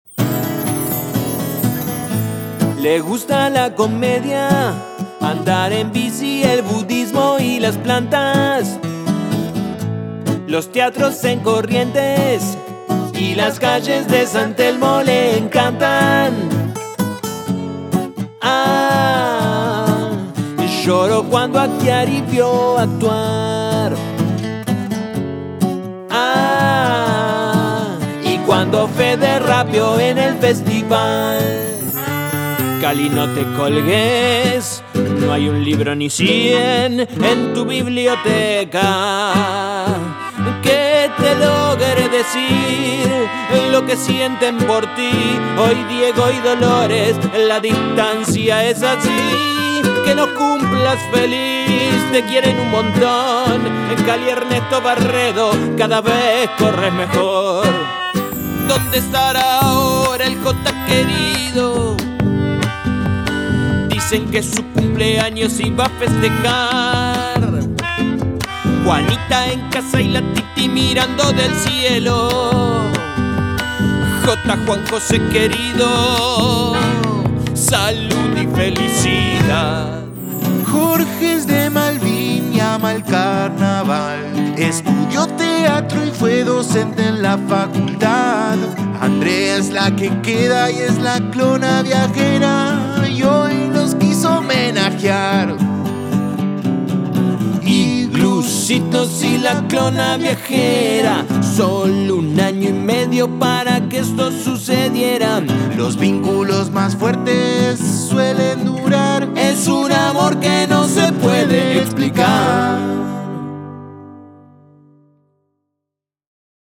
a dos voces y guitarras acústicas.